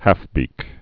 (hăfbēk, häf-)